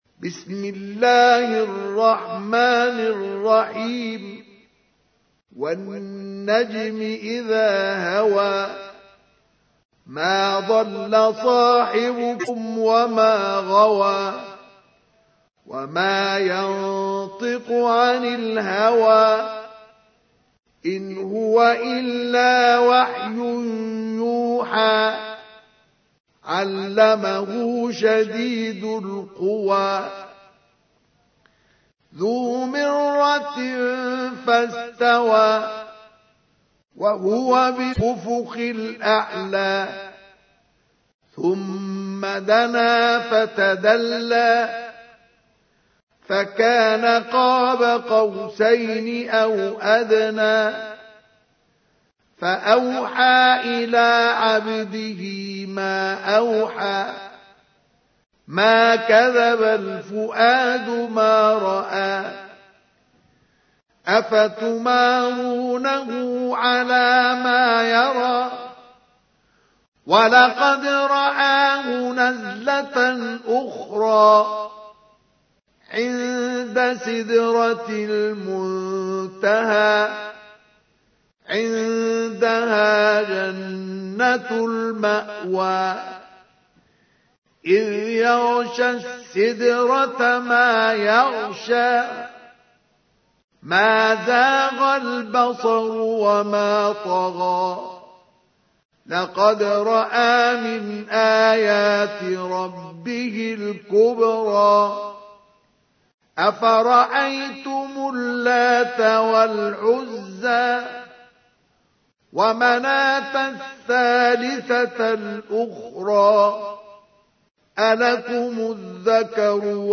تحميل : 53. سورة النجم / القارئ مصطفى اسماعيل / القرآن الكريم / موقع يا حسين